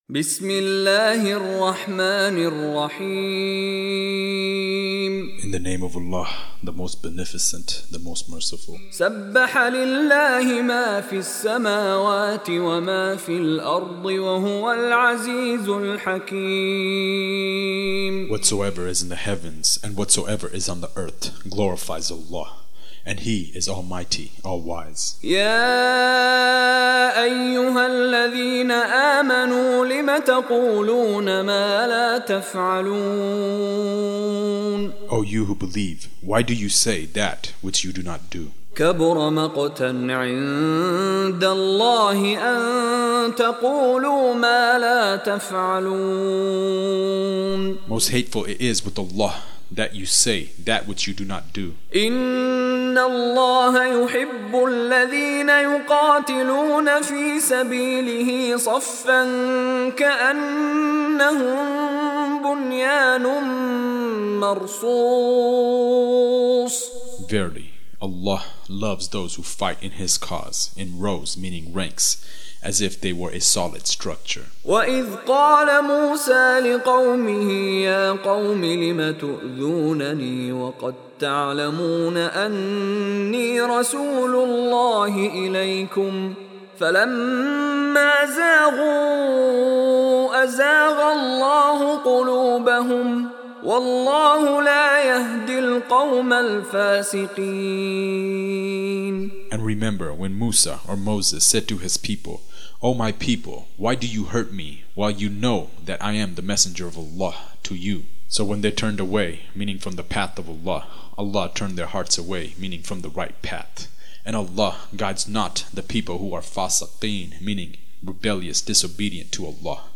Surah Repeating تكرار السورة Download Surah حمّل السورة Reciting Mutarjamah Translation Audio for 61. Surah As-Saff سورة الصف N.B *Surah Includes Al-Basmalah Reciters Sequents تتابع التلاوات Reciters Repeats تكرار التلاوات